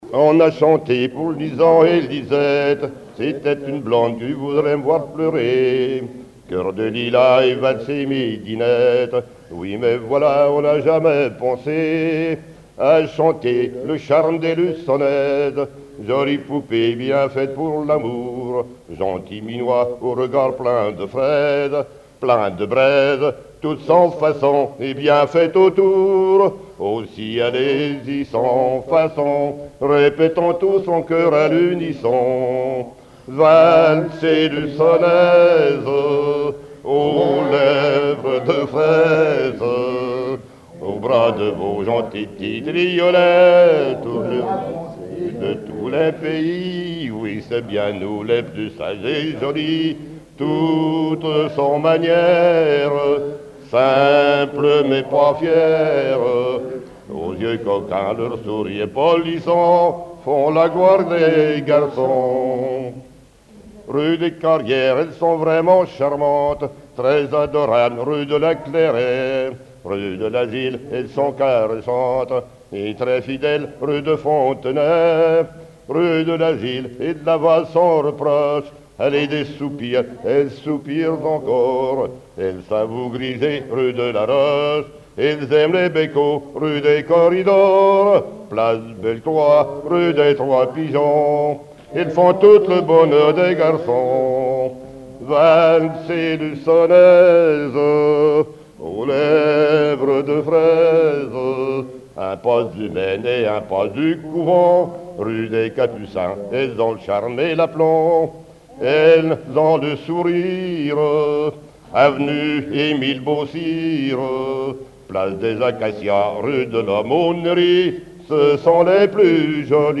Genre strophique
Regroupement de témoins ; chanteuses, chanteurs, musiciens
Pièce musicale inédite